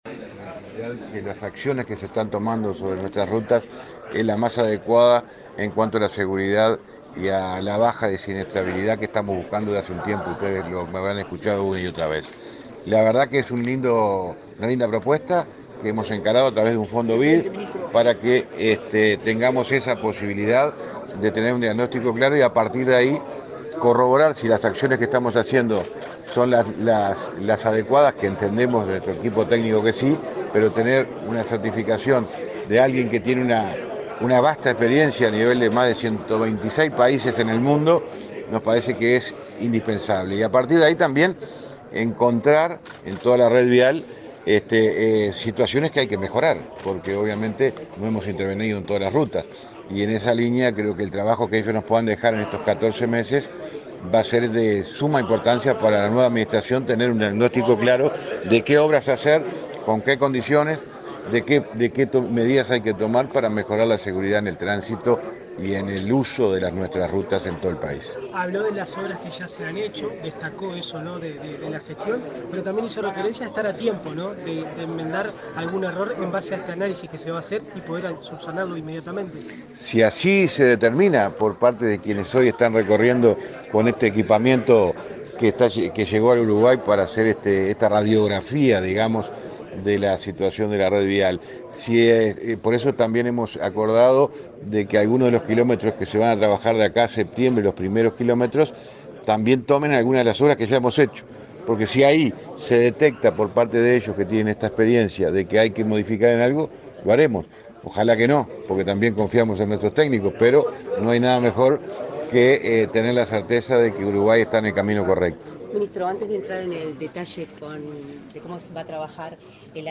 Palabras de autoridades en acto en el MTOP